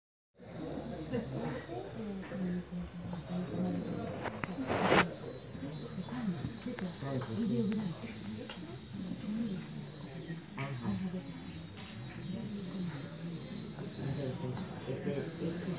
Lots of chanting, with drums and bells.
Photos and video were expressly forbidden inside the temple, but I used my phone to record the audio.
16 seconds of Temple Sounds
Kokedera Writing Room.m4a